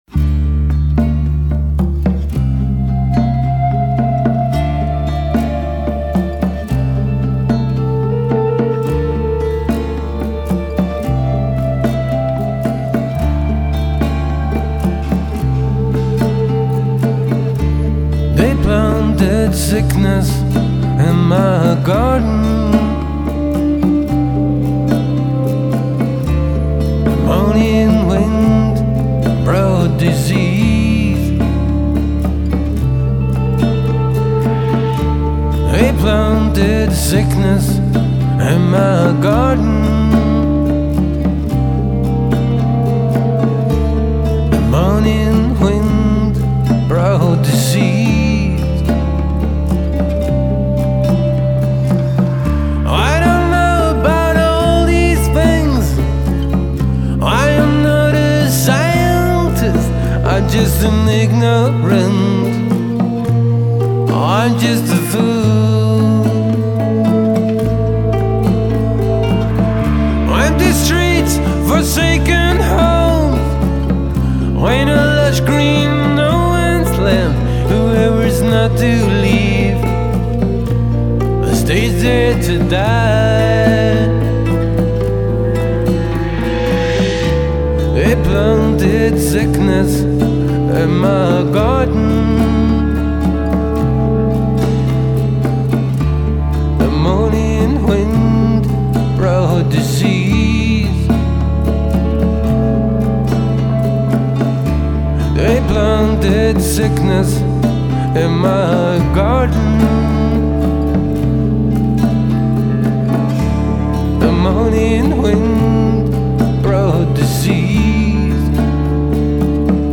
drums
bass
nylon string guitar
electric guitar
vocals, acoustic guitar